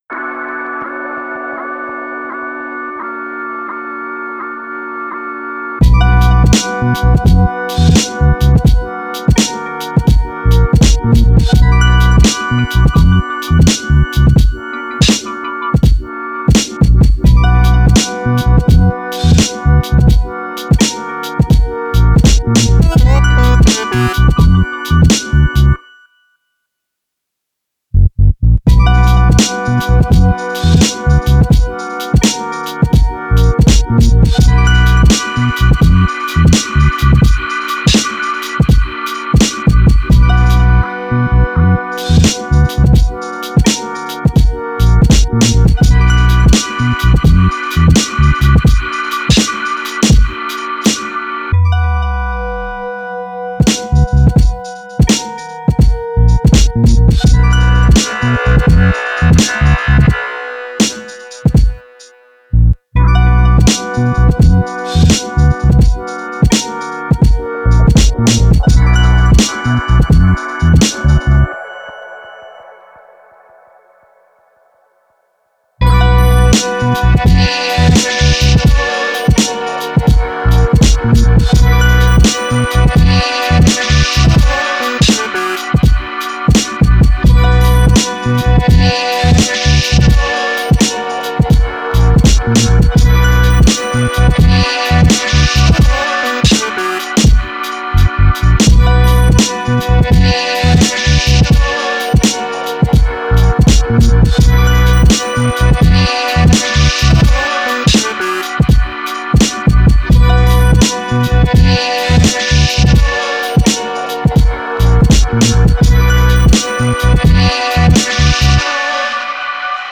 Heavy, deep and dreamy.
Cinematic hip hop beat and darkness with voice texture.